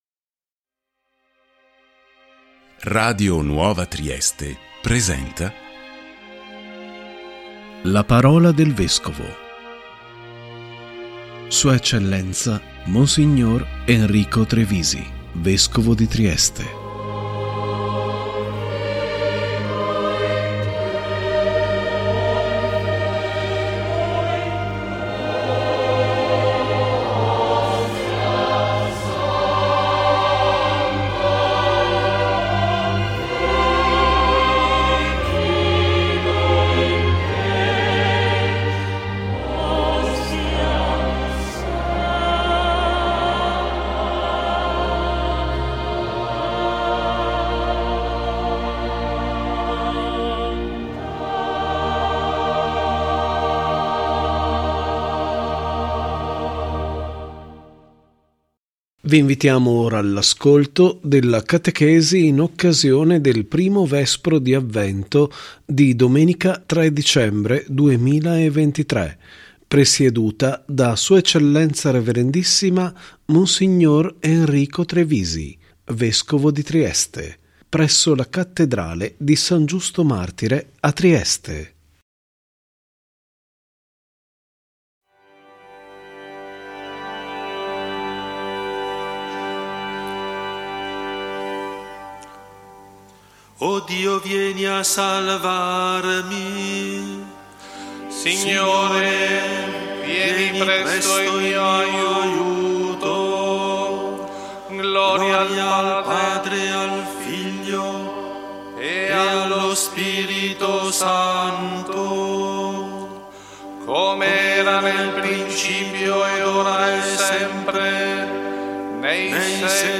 ♦ si sono tenuti, Domenica 3 dicembre 2023, il Canto del Vespro nella I Domenica di Avvento nell’anno liturgico B e la catechesi presieduta da Sua Eccellenza Rev.issima Mons. Enrico Trevisi, Vescovo di Trieste, presso la Cattedrale di San Giusto martire a Trieste.